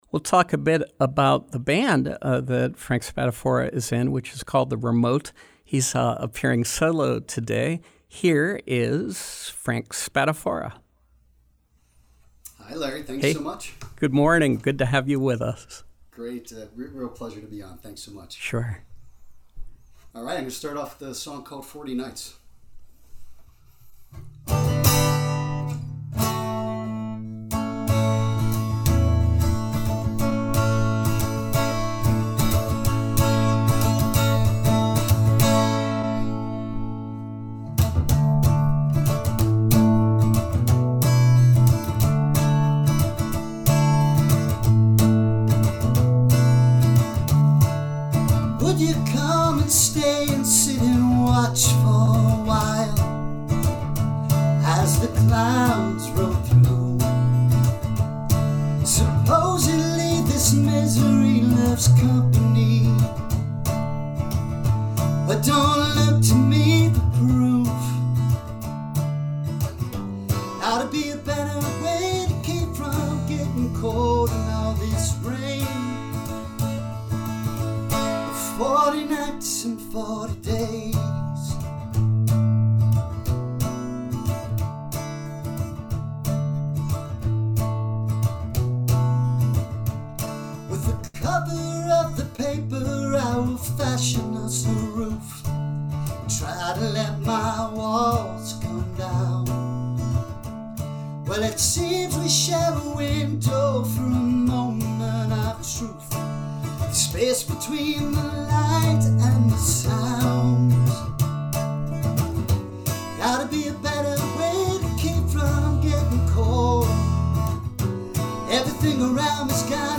Live music